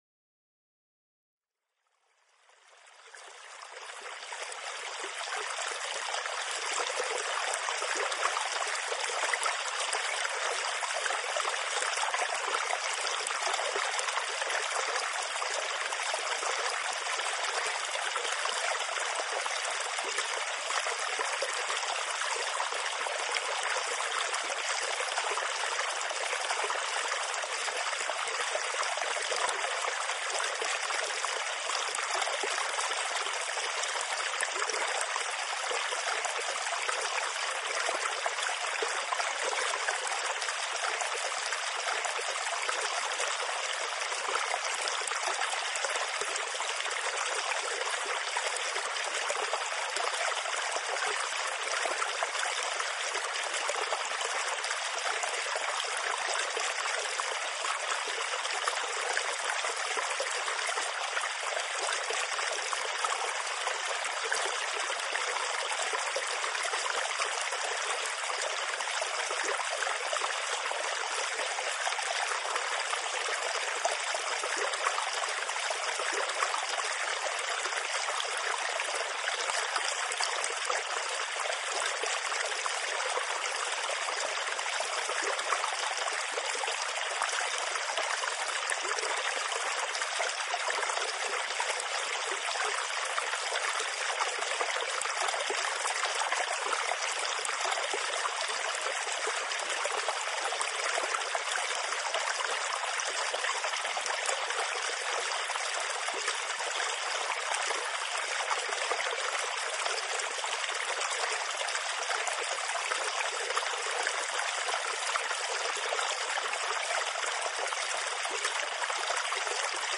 Water_Sounds_Relaxation_1.mp3
Water-Sounds-Relaxation-1.mp3